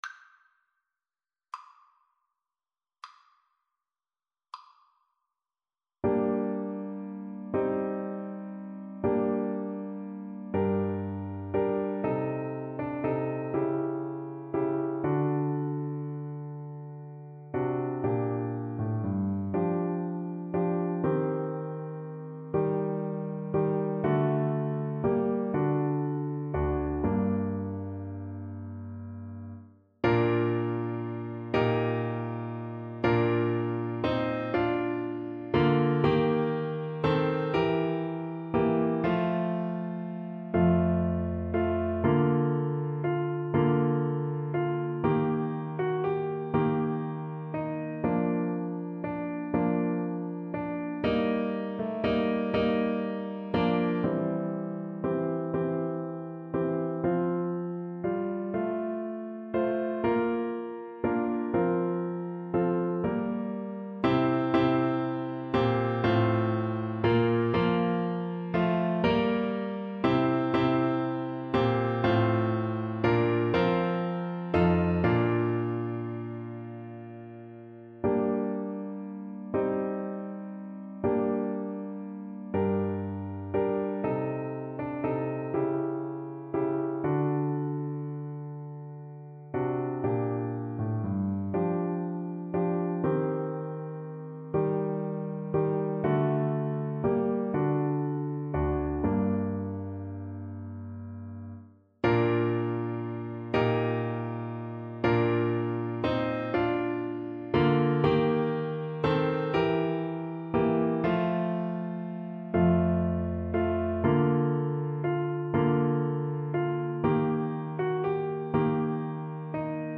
Clarinet version
12/8 (View more 12/8 Music)
D5-Bb6